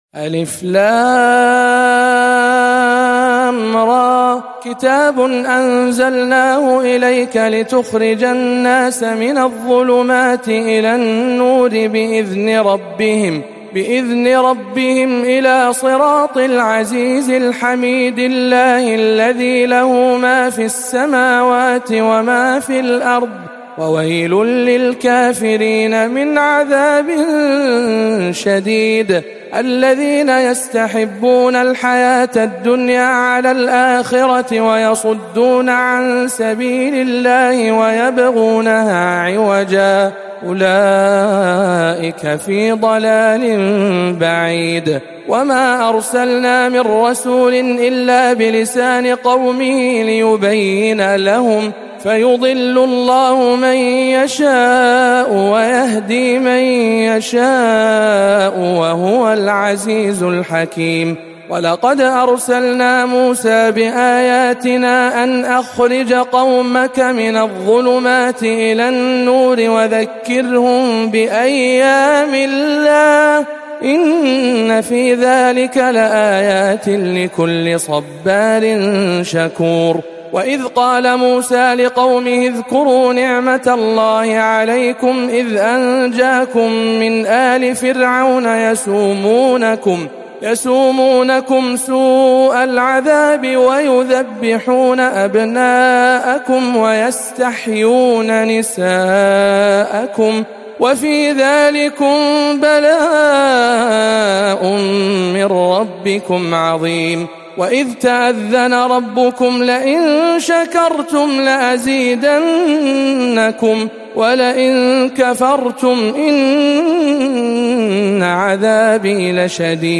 سورة إبراهيم بجامع أم الخير بجدة